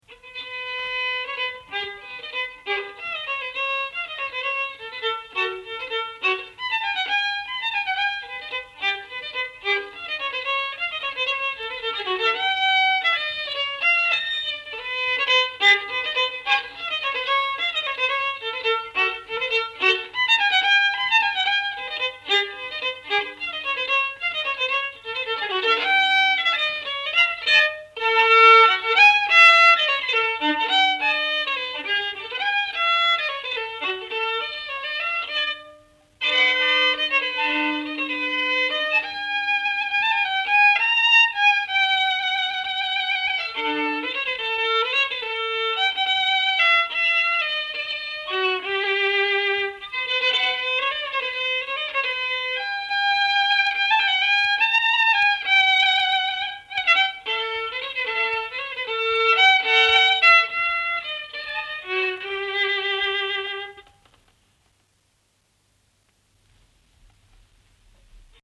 Vals / Valss